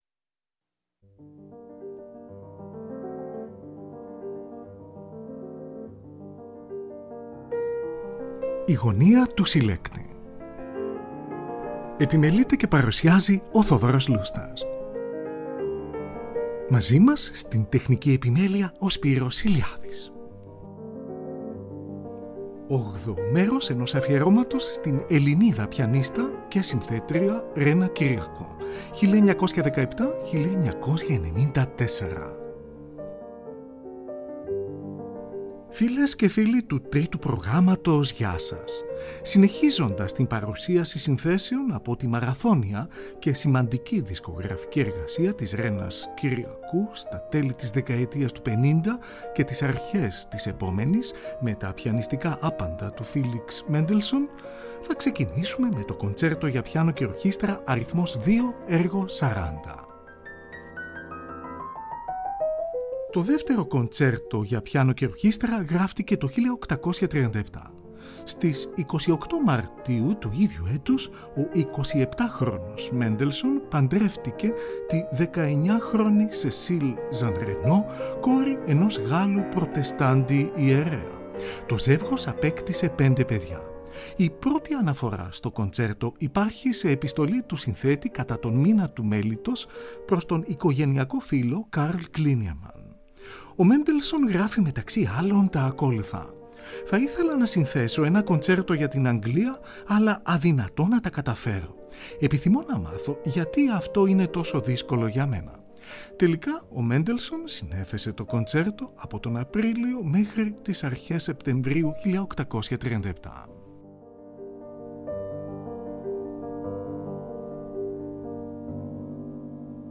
Τη Ρένα Κυριακού συνοδεύει η Συμφωνική της Βιέννης υπό τον Hans Swarowsky .
Εργα για Πιανο